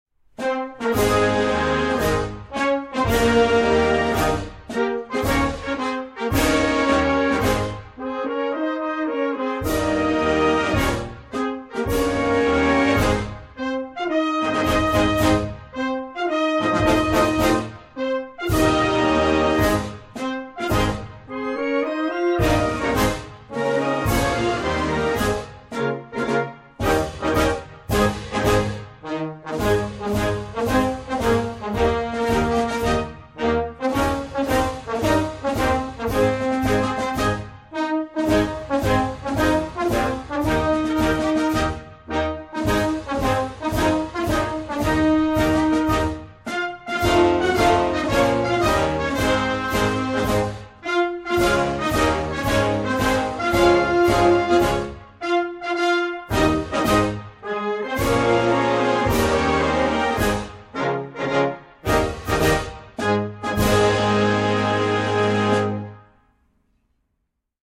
Libya,_Libya,_Libya_instrumental.mp3